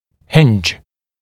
[hɪnʤ][хиндж]петля (дверная), шарнир; висеть, вращаться на петлях, шарнирах